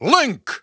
The announcer saying Link's name in English and Japanese releases of Super Smash Bros. Brawl.
Link_English_Announcer_SSBB.wav